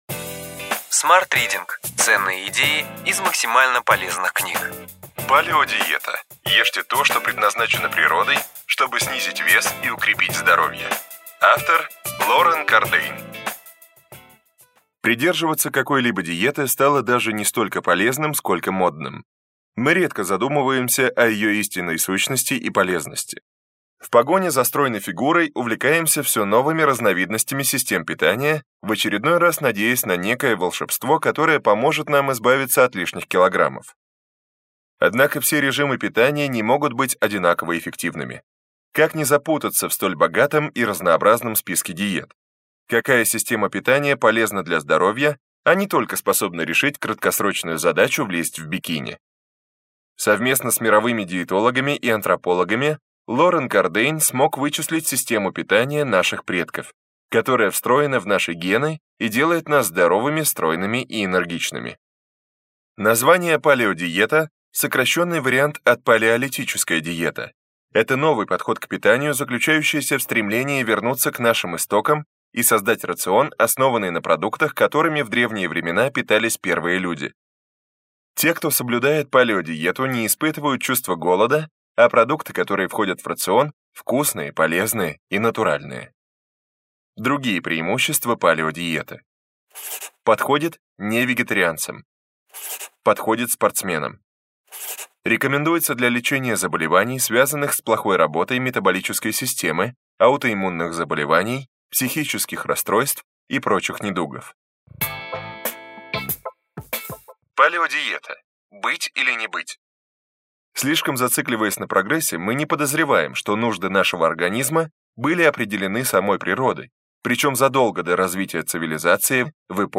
Аудиокнига Ключевые идеи книги: Палеодиета. Ешьте то, что предназначено природой, чтобы снизить вес и укрепить здоровье.